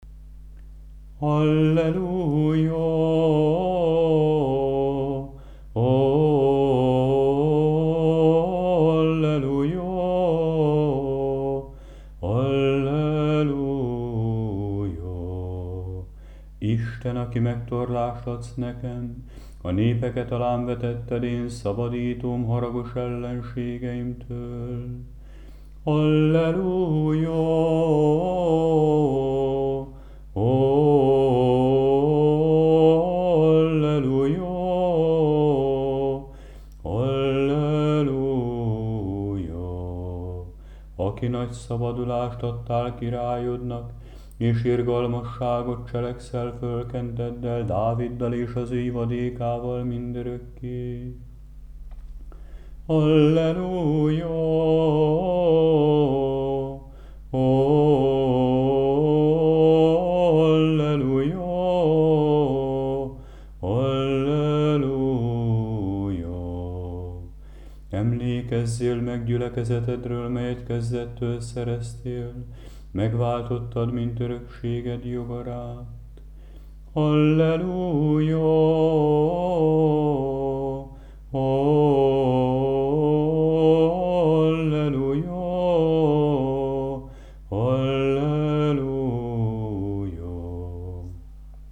11. Alleluja, 1. és 1. hang, föltám. és Szent Kereszt.mp3